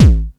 T4_Kik1.wav